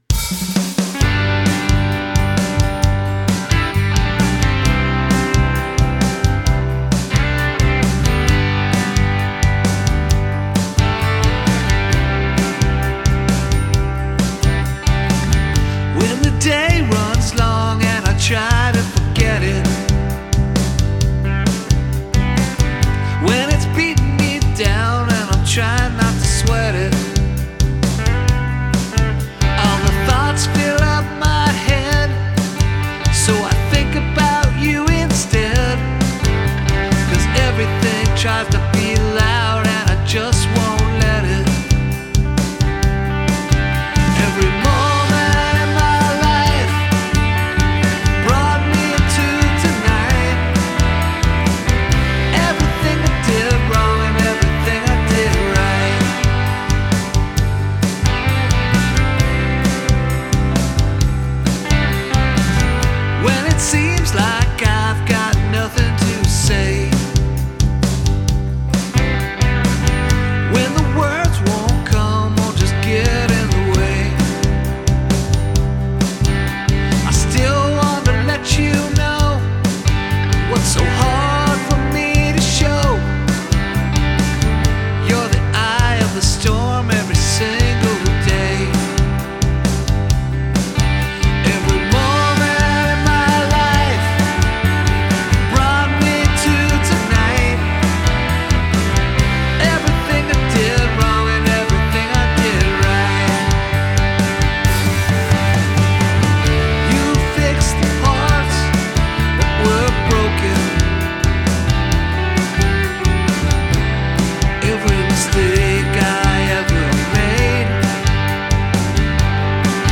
This one is really catchy!